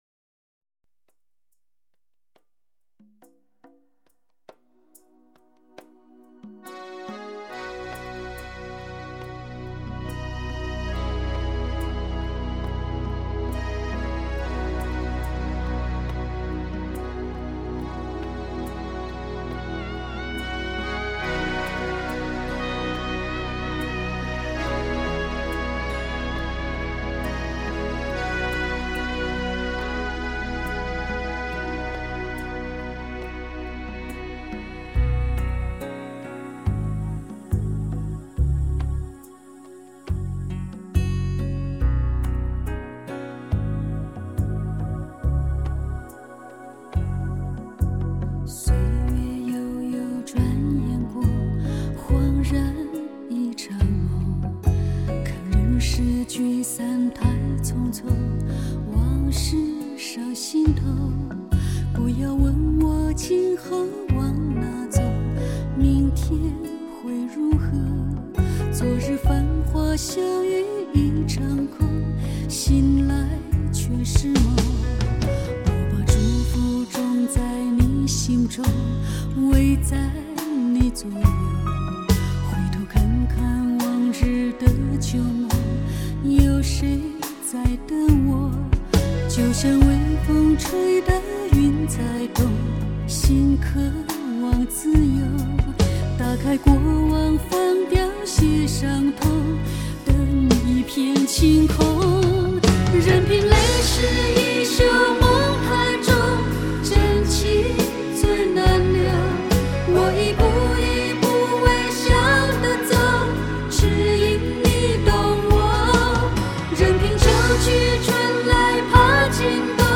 温柔 隽永 经典的声音